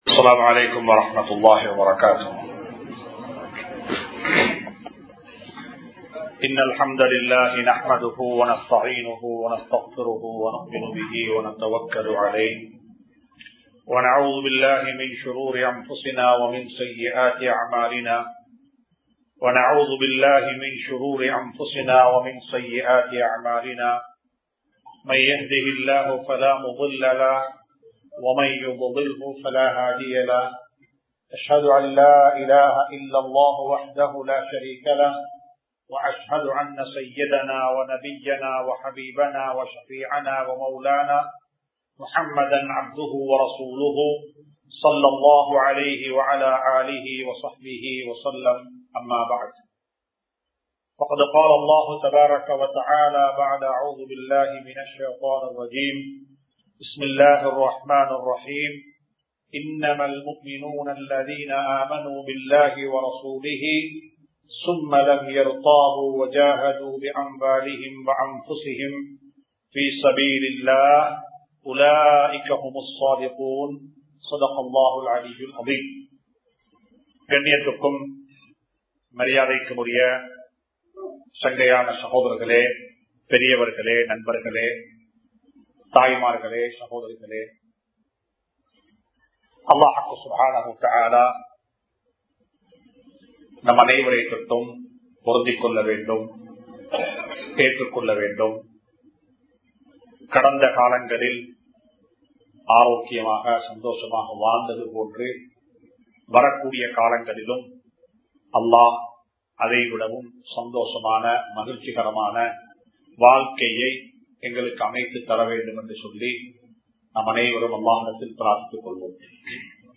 Islam Koorum Vaalkai Murai (இஸ்லாம் கூறும் வாழ்க்கை முறை) | Audio Bayans | All Ceylon Muslim Youth Community | Addalaichenai
Muhiyadeen Jumua Masjith